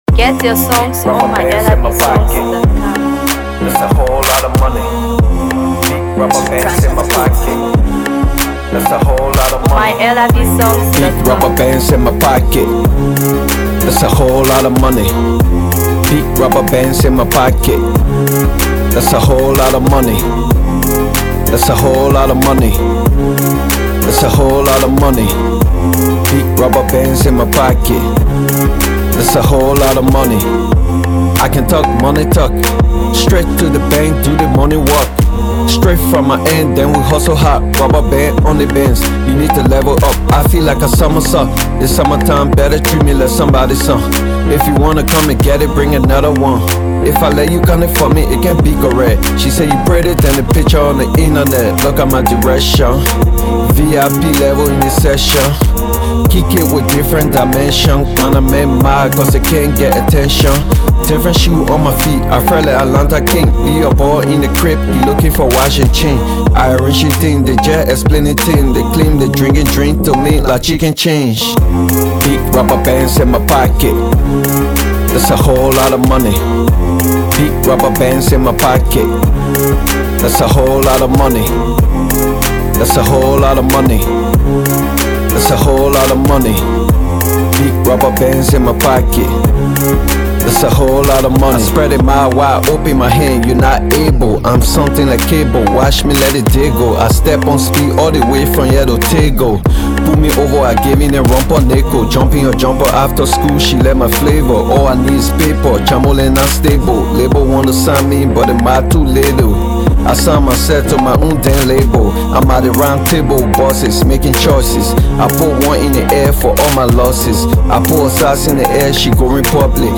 Hip HopMusic